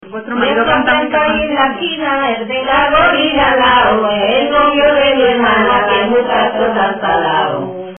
Materia / geográfico / evento: Canciones de amor Icono con lupa
Arenas del Rey (Granada) Icono con lupa
Secciones - Biblioteca de Voces - Cultura oral